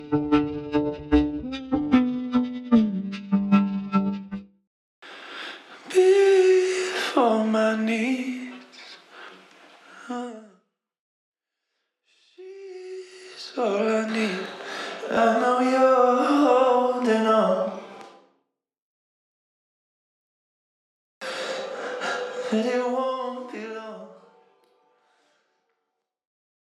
Studio Bassline Stem
Studio High Textures Stem
Studio Interstelar Keys Stem
Studio Leading Vocals Stem
Studio Stabed Strings Stem